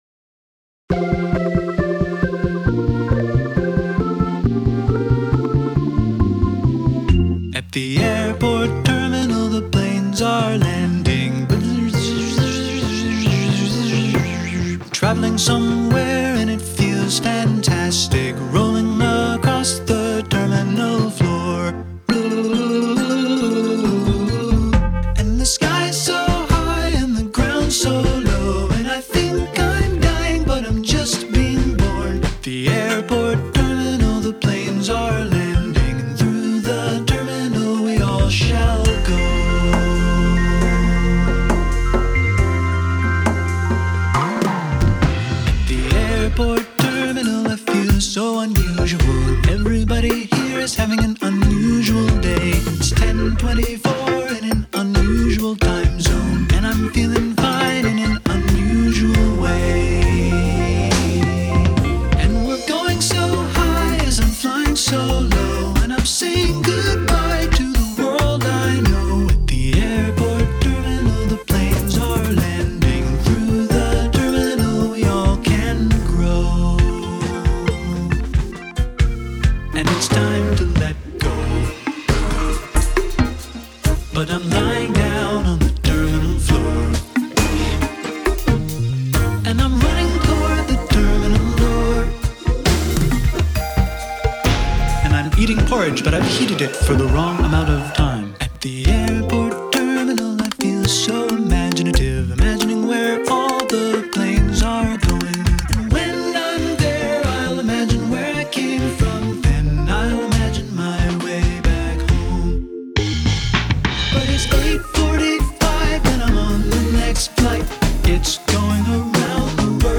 BPM136
Audio QualityPerfect (High Quality)
Overall, it's a pretty catchy song.